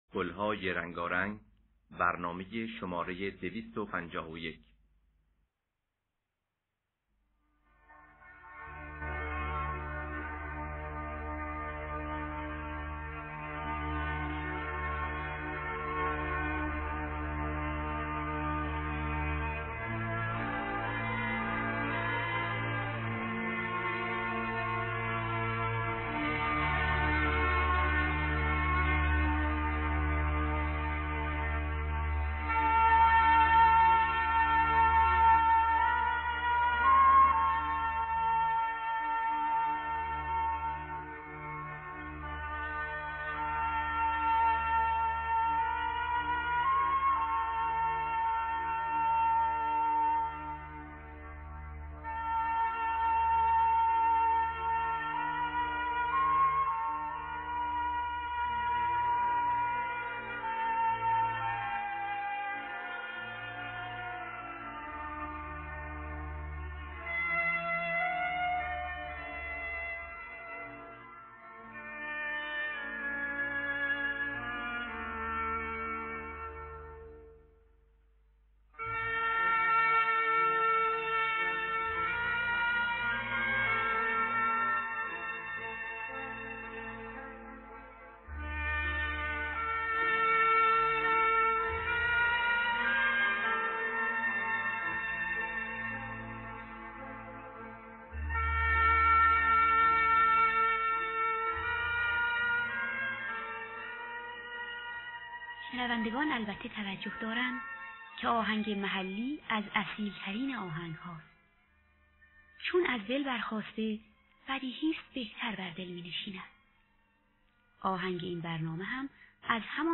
دانلود گلهای رنگارنگ ۲۵۱ با صدای بنان، حسین قوامی، اکبر گلپایگانی در دستگاه همایون.